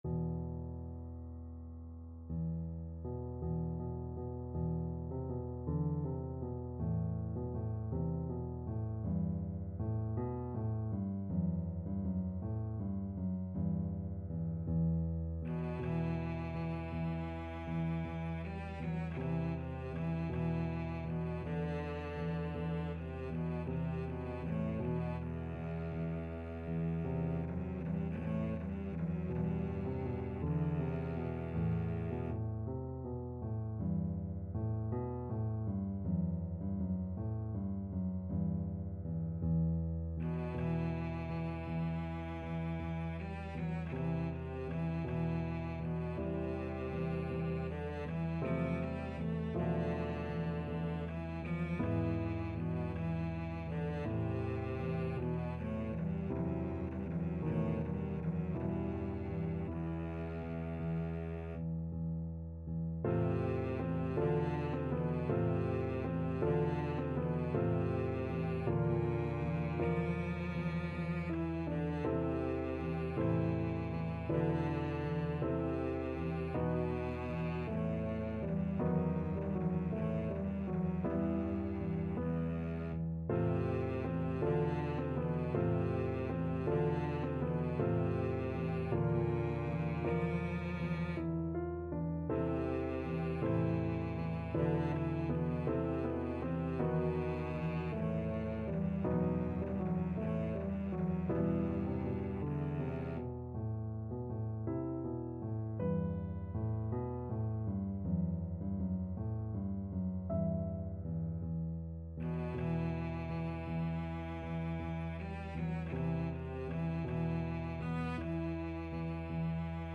Cello
E minor (Sounding Pitch) (View more E minor Music for Cello )
6/8 (View more 6/8 Music)
~ = 80 Andantino molto cantabile e con dolore (View more music marked Andantino)
E3-E5
Classical (View more Classical Cello Music)